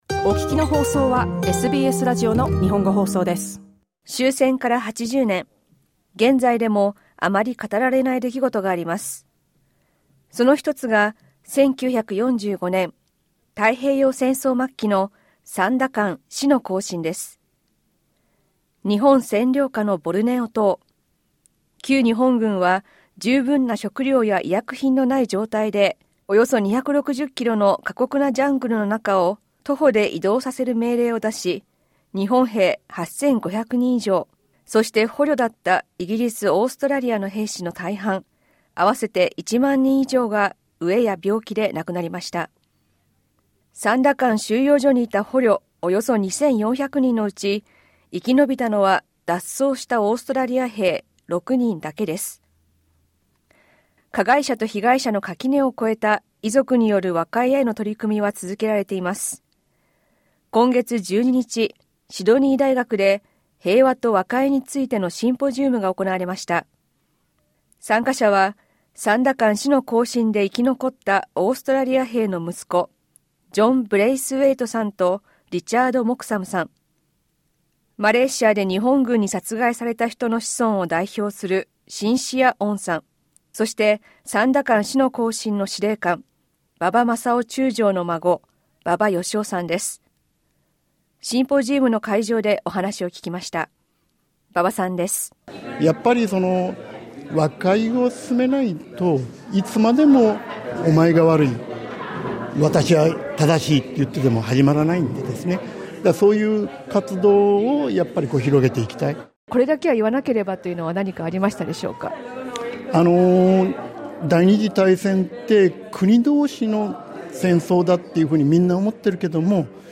日本兵や英豪の捕虜、約一万人以上が戦闘ではなく飢えと病気で命を落としました。加害者と被害者が向き合う和解への取り組み、シドニー大学で行われたシンポジウムでお話を聞きました。